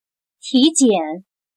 体检 \ tǐjiǎn \examen físico